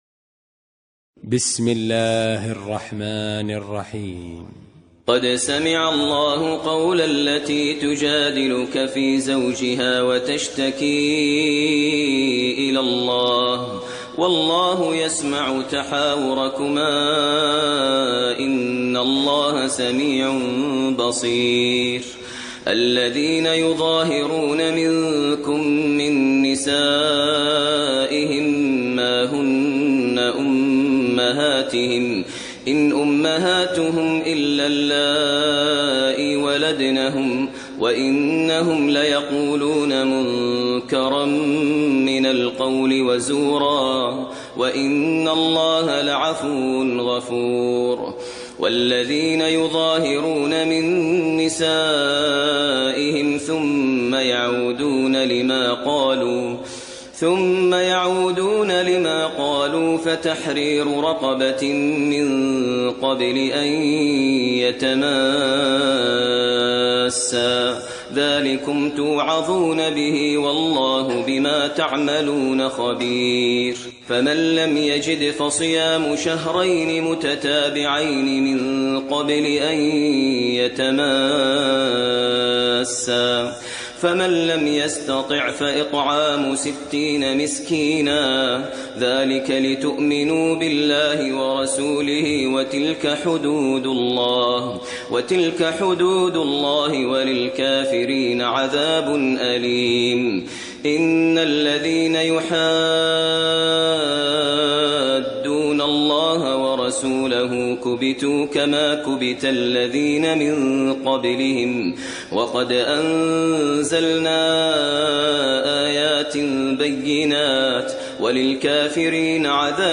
ترتیل سوره مجادله با صدای ماهر المعیقلی
058-Maher-Al-Muaiqly-Surah-Al-Mujadila.mp3